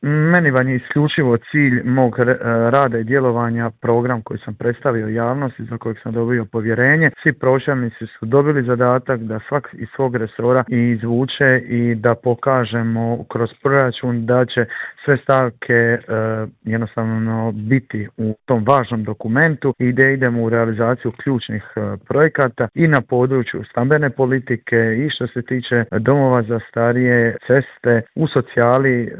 U Intervju Media servisa razgovarali smo s gradonačelnikom Splita Tomislavom Šutom koji nam je prokomentirao aktualnu situaciju i otkrio je li spreman za eventualne izvanredne izbore.